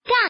Index of /mahjong_gansu_test/update/1686/res/sfx/woman/